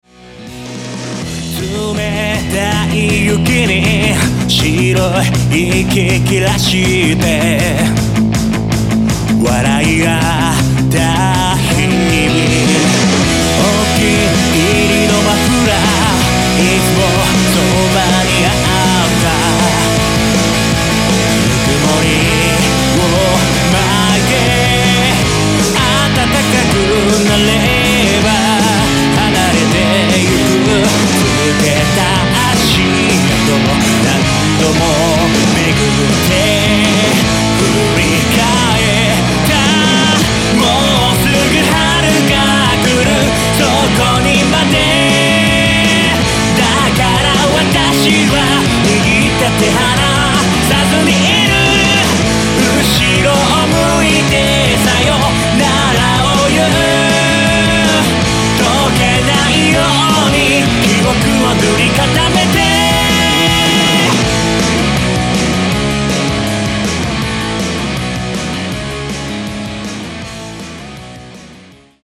クロスフェードデモ